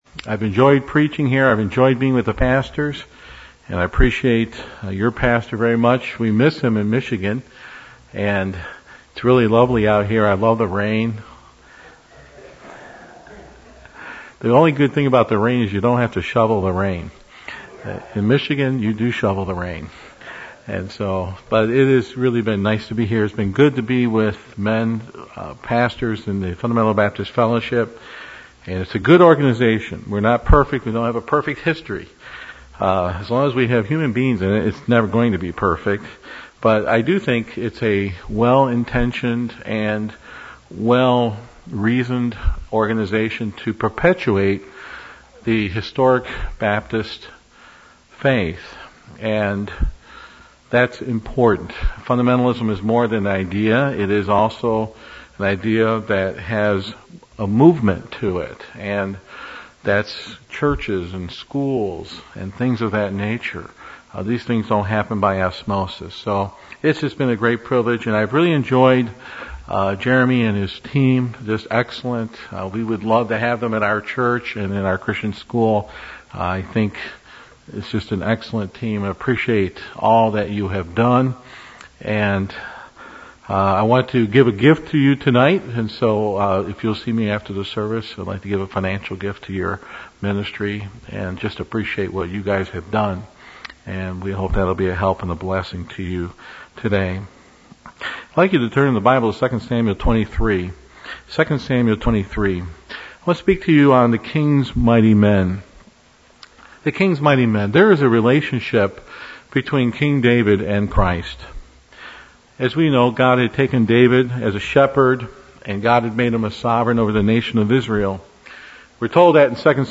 2013 The Kings Mighty Men Preacher